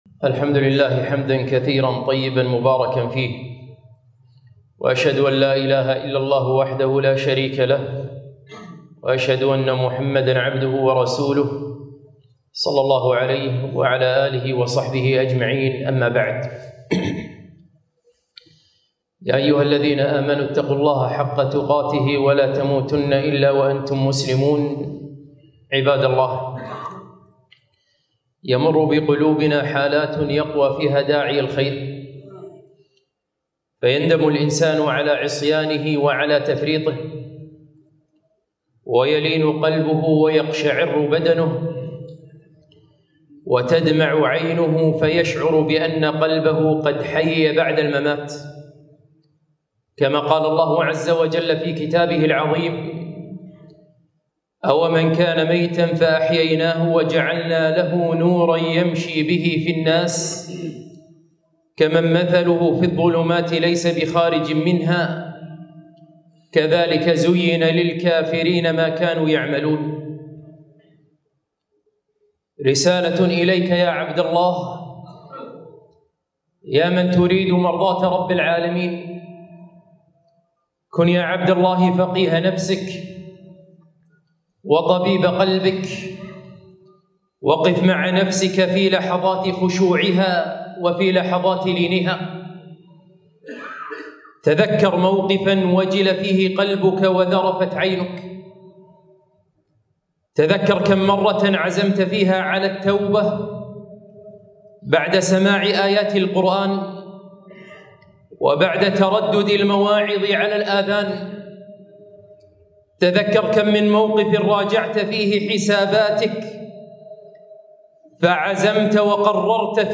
خطبة - يا من تريد إصلاح قلبك